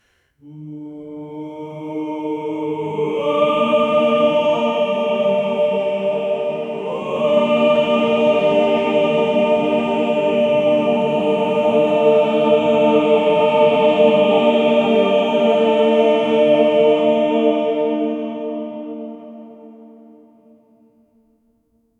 choir-with-well-blended-s-frpthku5.wav